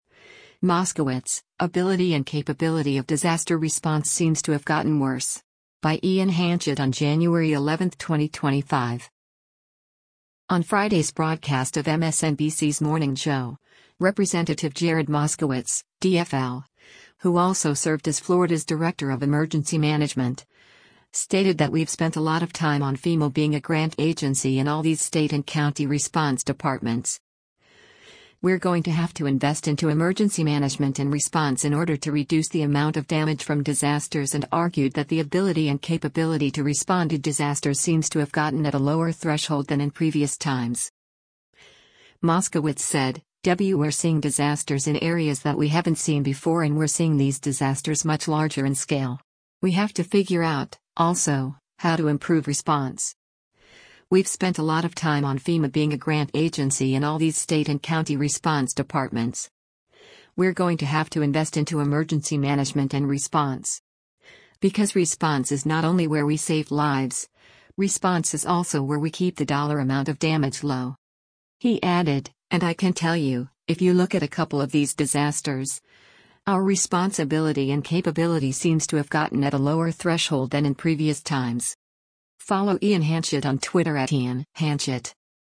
On Friday’s broadcast of MSNBC’s “Morning Joe,” Rep. Jared Moskowitz (D-FL), who also served as Florida’s Director of Emergency Management, stated that “We’ve spent a lot of time on FEMA being a grant agency and all these state and county response departments. We’re going to have to invest into emergency management and response” in order to reduce the amount of damage from disasters and argued that the ability and capability to respond to disasters “seems to have gotten at a lower threshold than in previous times.”